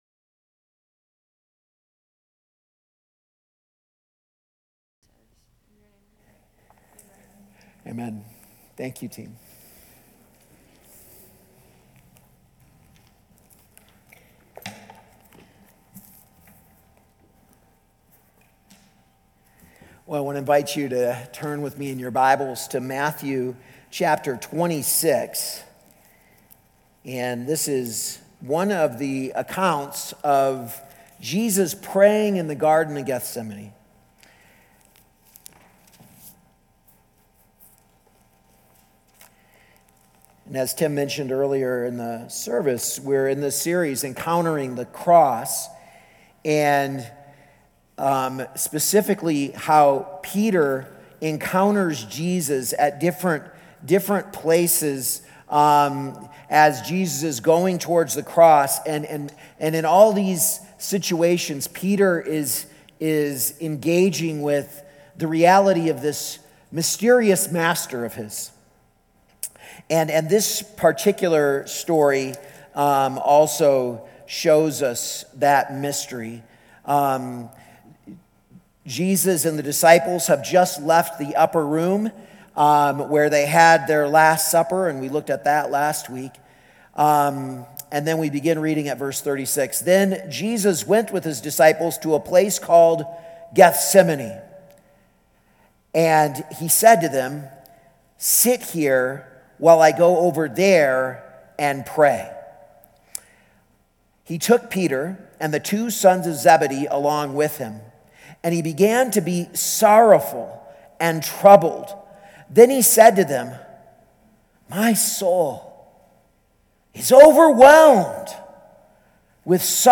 A message from the series "Encountering the Cross."